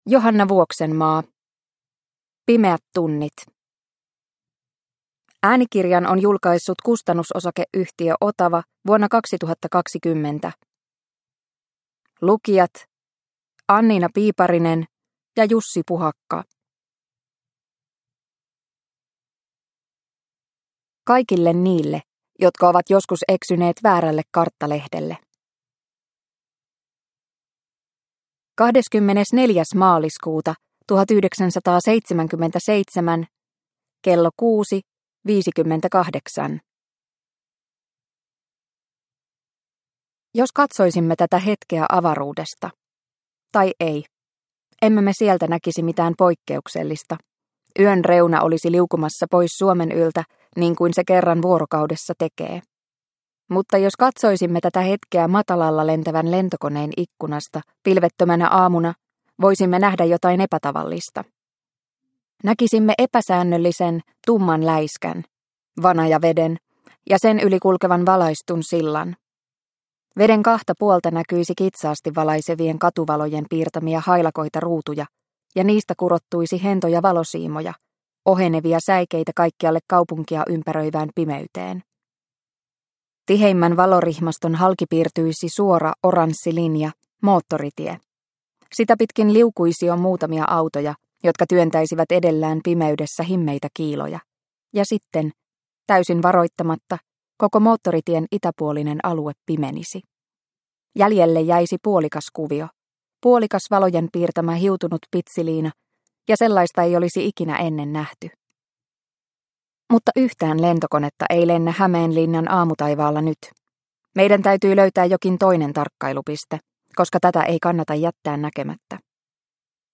Pimeät tunnit – Ljudbok – Laddas ner